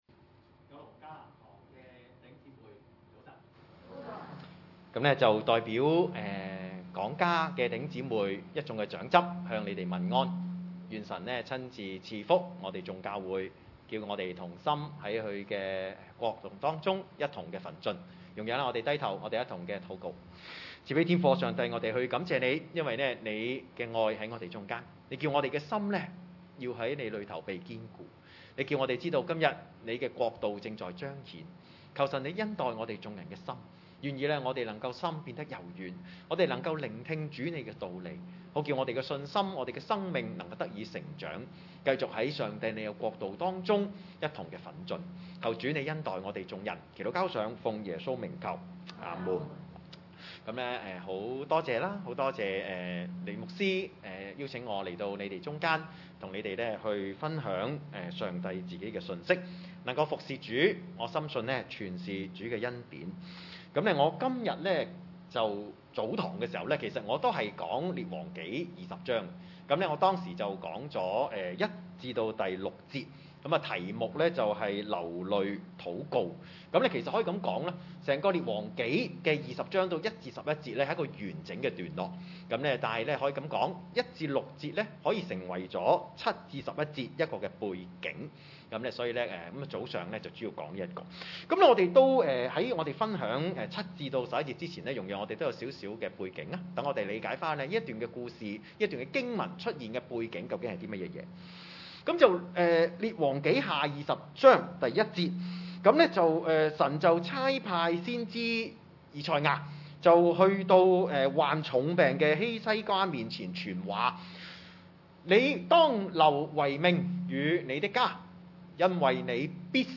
經文: 經文 – 列王紀下 20：7-11 崇拜類別: 主日午堂崇拜 7.